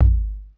Waka KICK Edited (69).wav